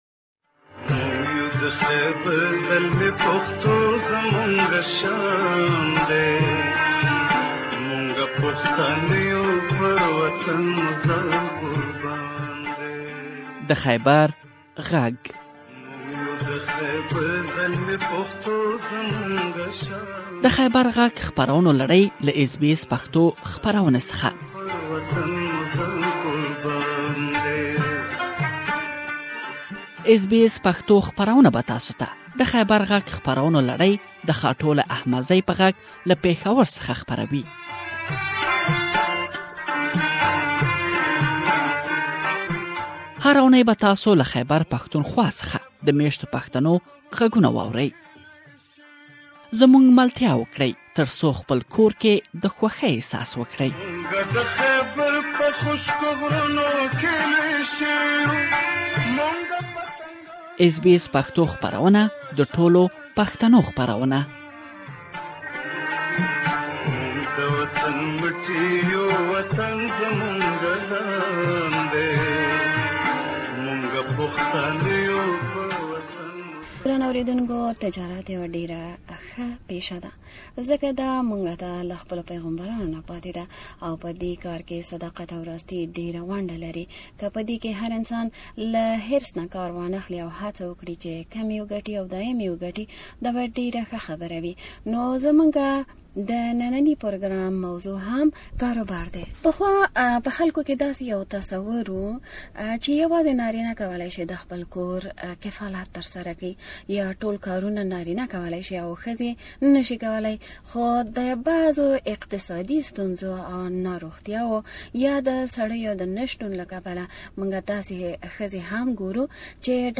Today we would like to share an interview with a woman shopkeeper who stands on her own and proudly doing her job.